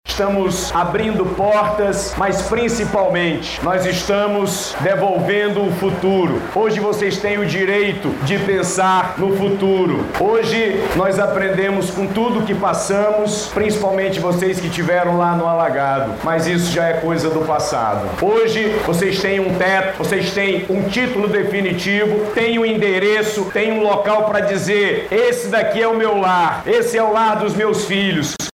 Durante a entrega, o governador do Amazonas, Wilson Lima, ressaltou a importância do resgate de pessoas que viviam em zonas de alta vulnerabilidade.